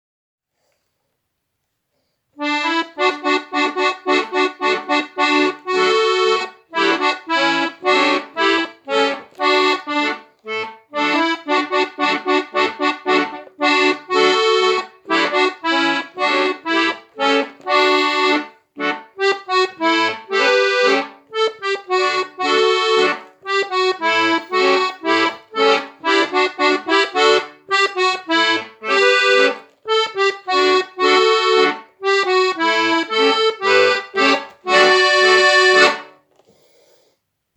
Akkordeon lernen mit 50 plus
Musste halt das Handy herhalten. Leider hat das Handy mit dem PC nicht zusammen wollen, dann die Datei halt mit E Mail verschickt und hier ist Tiritomba ... leider noch nicht ganz flüssig.